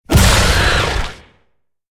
Sfx_creature_squidshark_roar_01.ogg